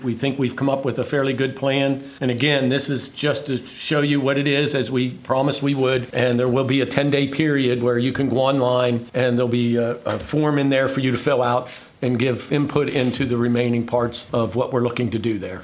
A layout plan was presented for the old Allegany High School neighborhood project during last night’s Allegany County Commissioner meeting.  The plan that is being considered consists of 65 units which includes 35 town homes, 9 villa homes with an aging in place model, as well as detached single family homes.  Commissioner Bill Atkinson said the agreement with developer DR Horton would be to build 10 units at a time, and as they sell, the process will continue…